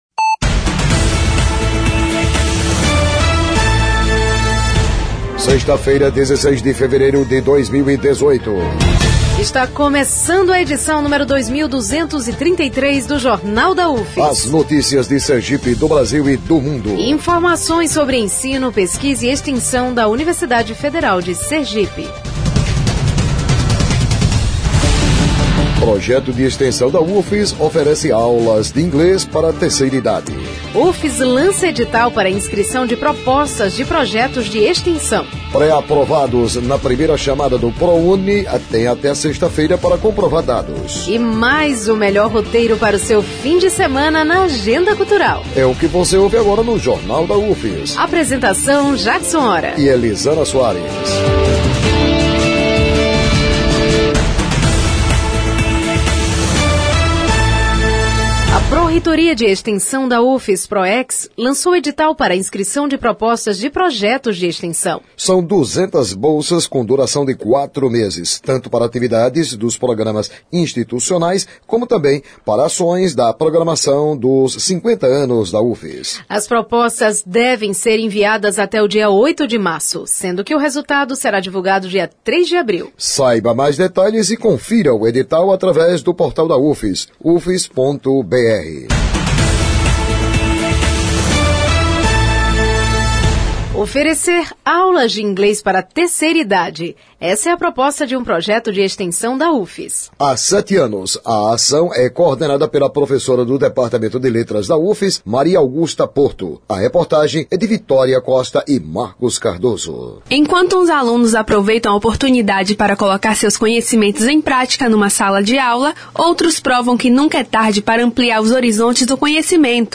O noticiário vai ao ar às 11h00 na Rádio UFS FM 92.1, com reprises às 17h00 e 22h00.